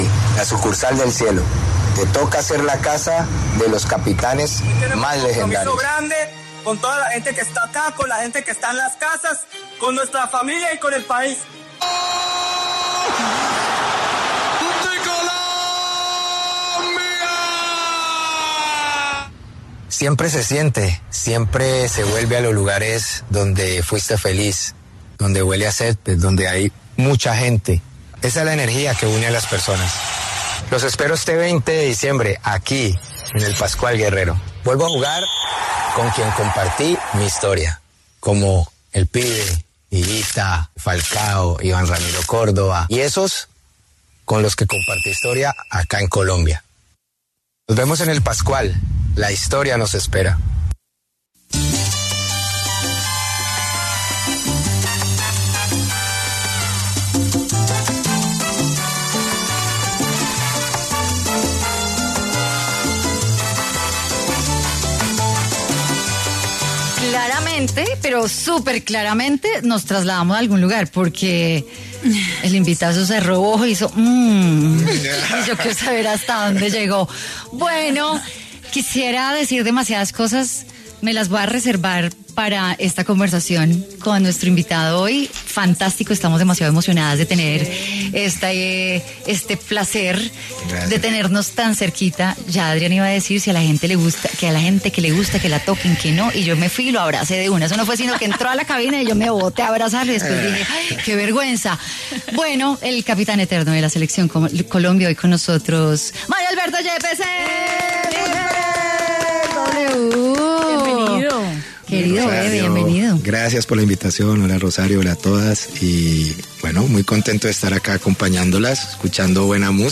El exfutbolista y técnico colombiano Mario Alberto Yepes pasó por los micrófonos de Mujeres W para charlar acerca de varias anécdotas de su carrera futbolística, sus aprendizajes y su despedida del deporte que se dará próximamente.